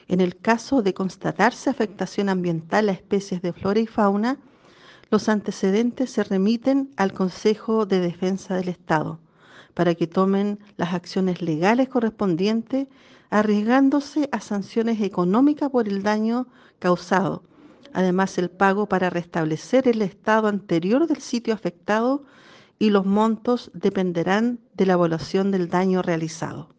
La directora de la Corporación Nacional Forestal Araucanía, Maria Teresa Huentequeo, indicó que en el caso de constatarse afectación ambiental a especies de flora y fauna, los responsables arriesgan multas.